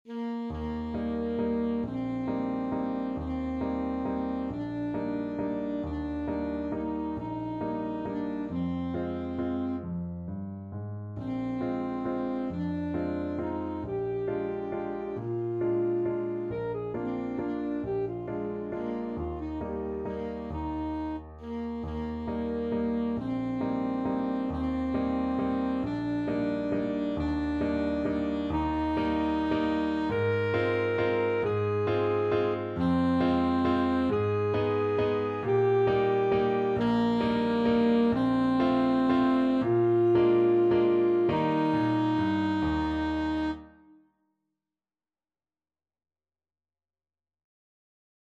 Alto Saxophone version
Alto Saxophone
3/4 (View more 3/4 Music)
G4-Bb5
Waltz .=45
Classical (View more Classical Saxophone Music)